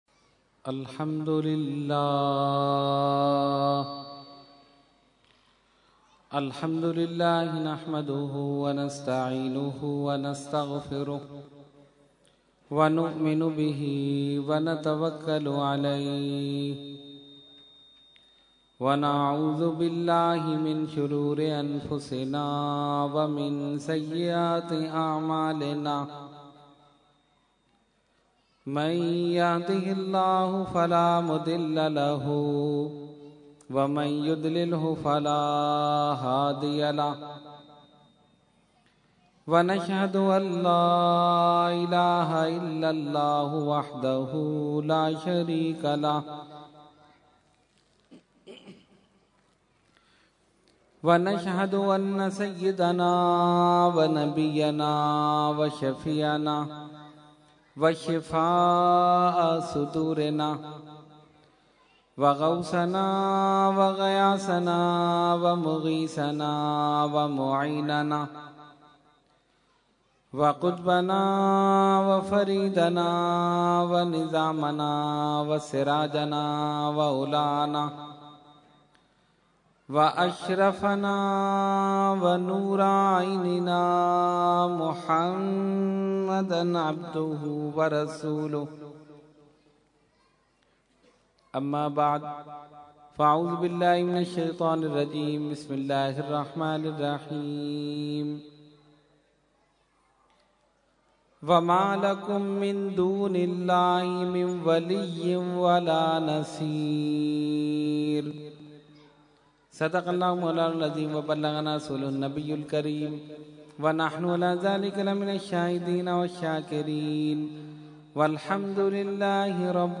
Category : Speech | Language : UrduEvent : Muharram 2012